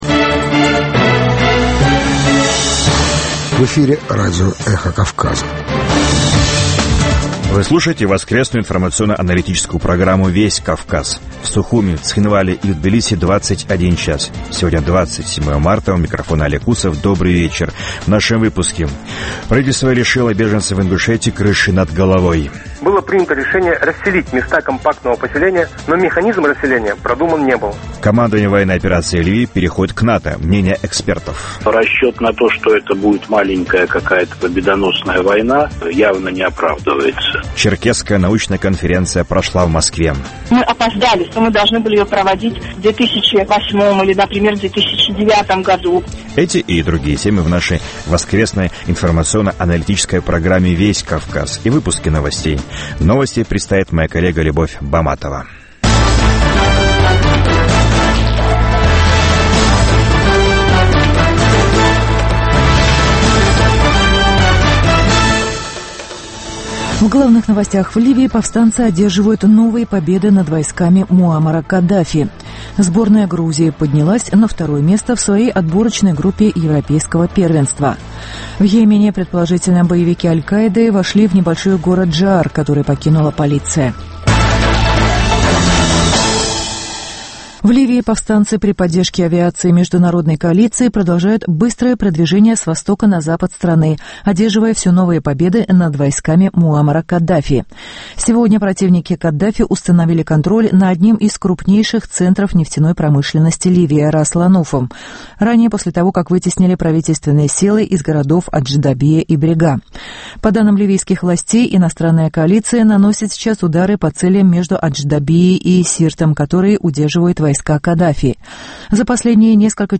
Новости, репортажи с мест, интервью с политиками и экспертами , круглые столы, социальные темы, международная жизнь, обзоры прессы, история и культура.